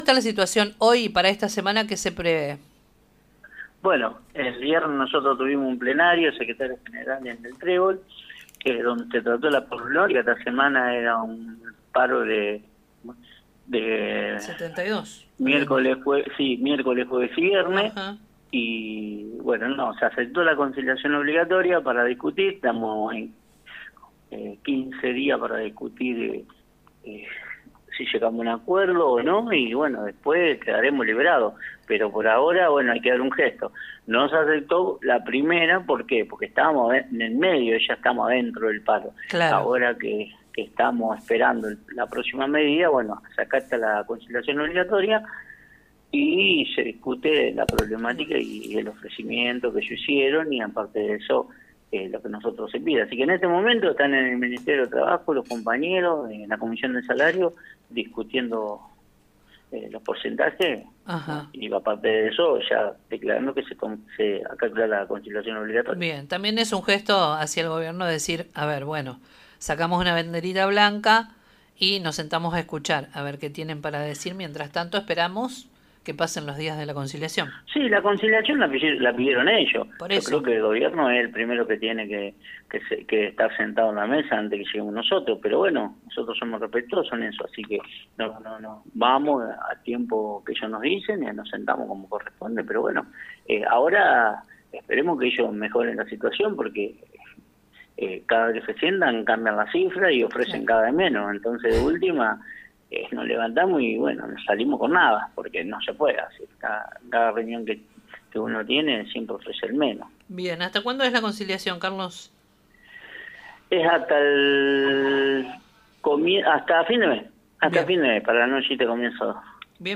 En diálogo con el programa Con Voz, de FM 102.9 Nueva Estrella